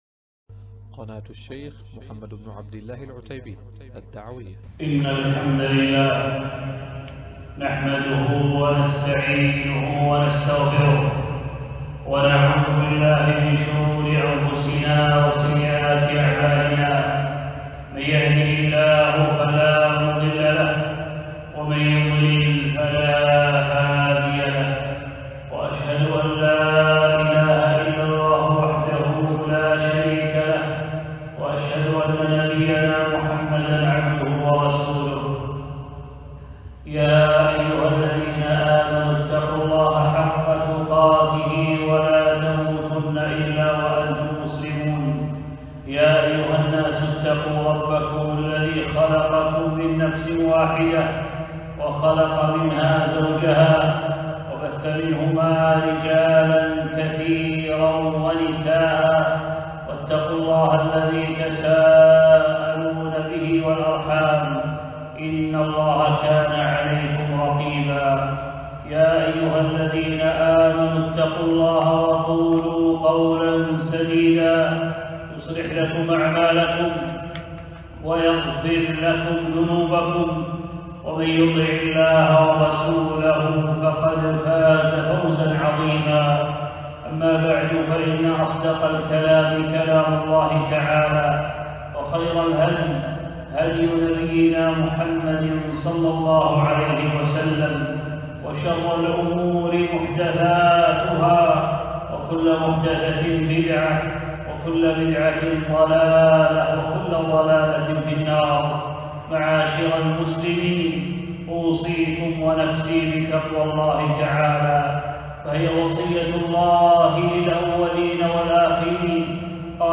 خطبة - فضل عشر ذي الحجة